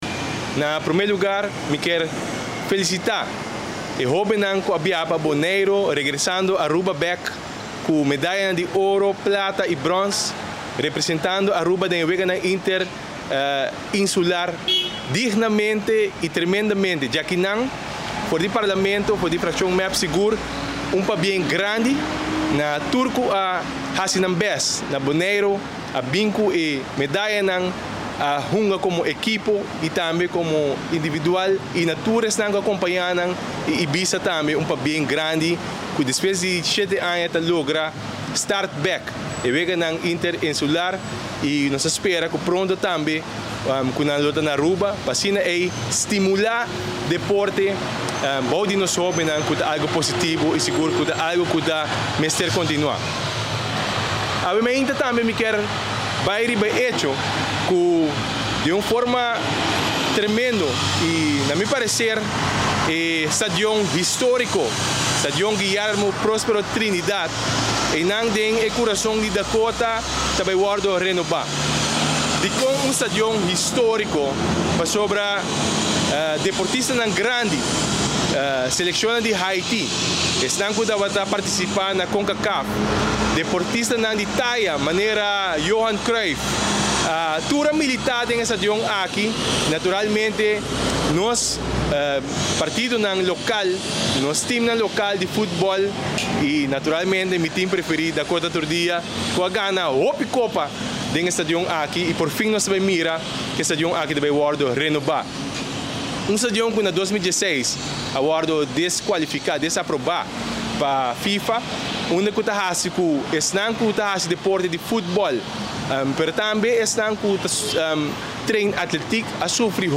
Den conferencia di prensa di MEP parlamentario Hendrik Tevreden ta contento y ta felicita e hobennan cu a participa na e weganan interinsular na Boneiro. Tambe e ta sumamente contento cu e stadion di Guillermo Prospero Trinidad ta bay mira un cambio poritivo.